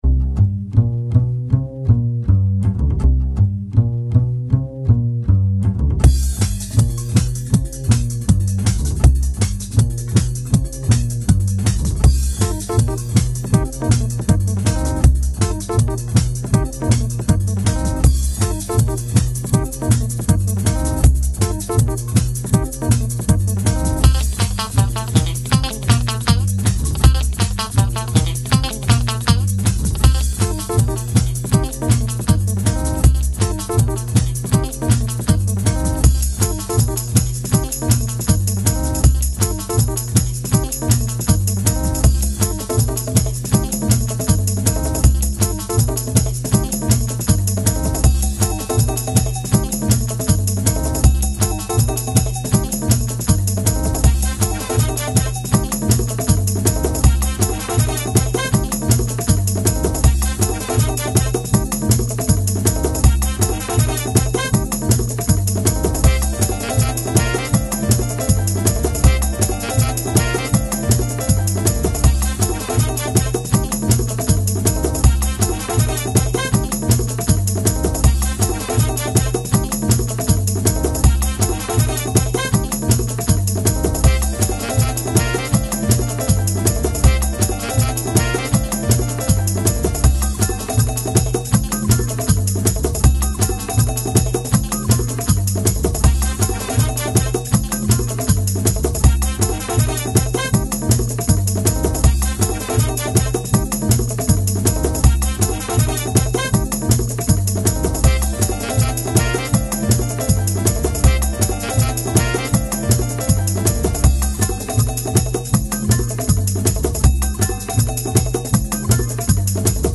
I sometimes write techno songs under the band name "The Pure Virtual Method".
The System Is Down The name is a joke from Strongbad's Email, but the song is more like upbeat jazz than techno.